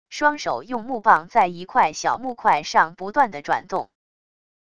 双手用木棒在一块小木块上不断地转动wav音频